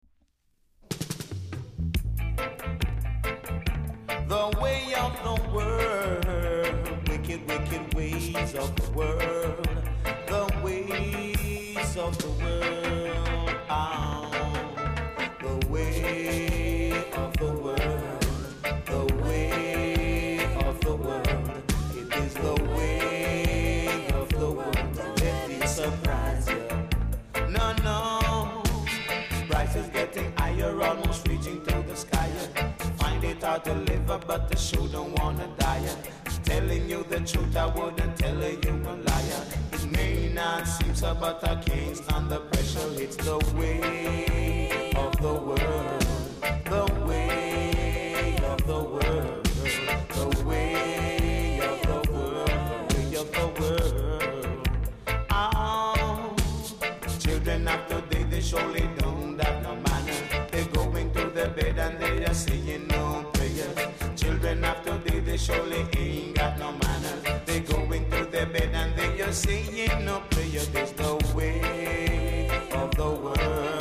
※小さなチリノイズが少しあります。
コメント VERY RARE DEEP VOCAL!!!!※レーベル両面とも汚れていますが、盤はキレイほうだと思います。